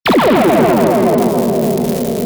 Laser 1
laser_1.wav